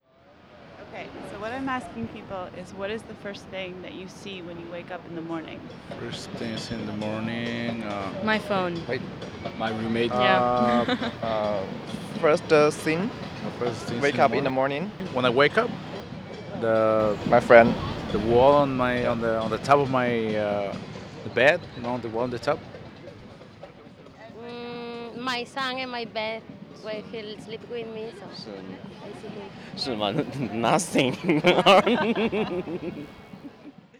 We went to Washington Square Park and got a few (dare I say) excellent recordings.